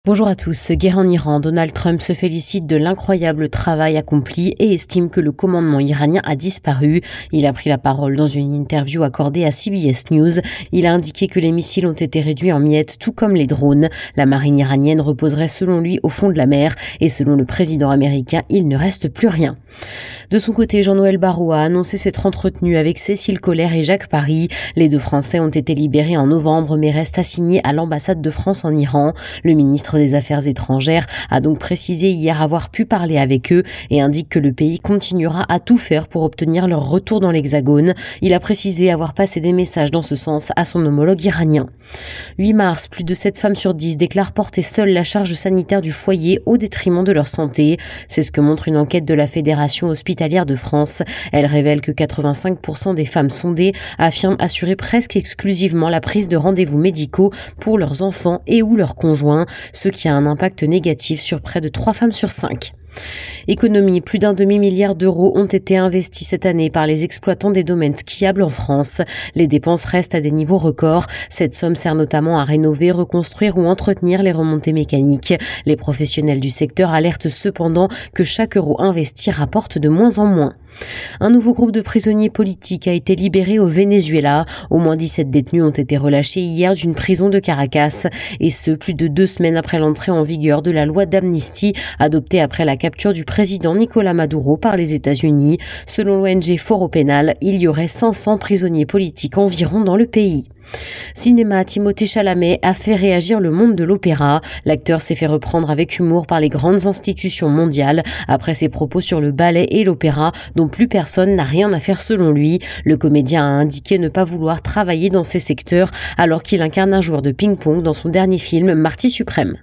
Flash infos 08/03/2026